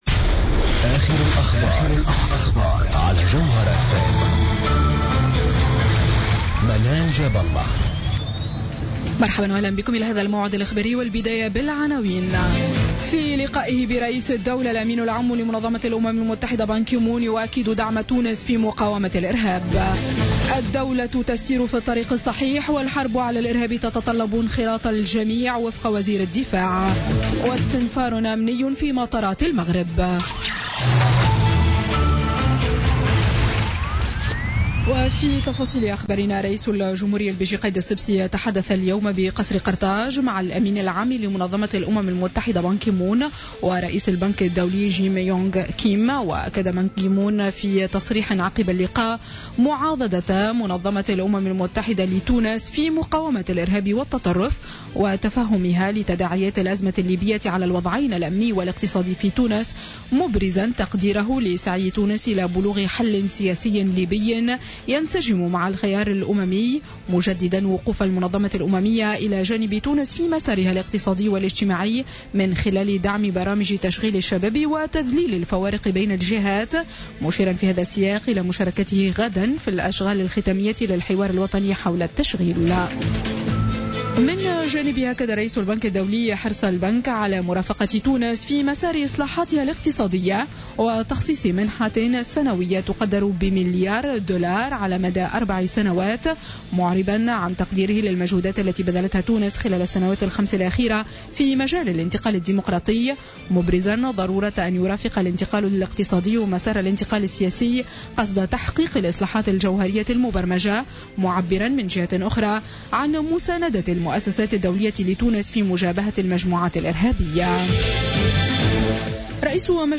نشرة أخبار السابعة مساء ليوم الاثنين 28 مارس 2016